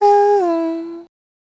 Isaiah-Vox_Hum_kr.wav